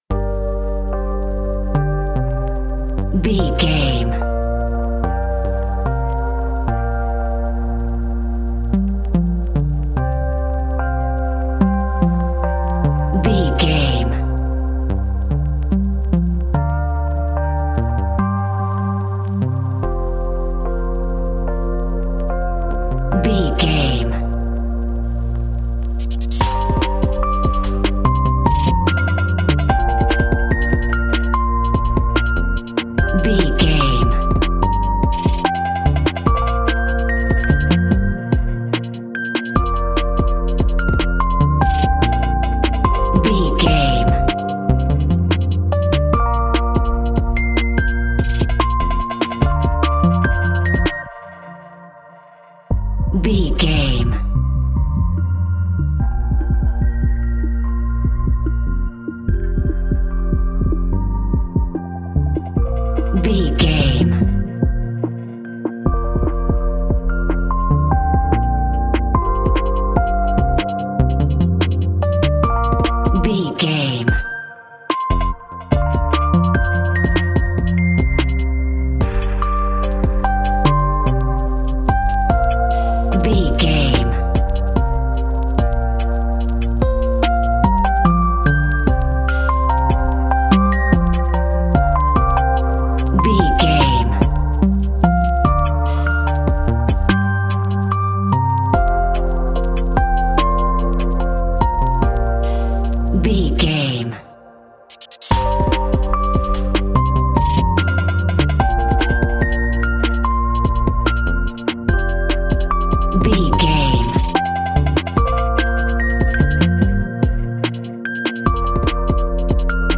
Ionian/Major
Fast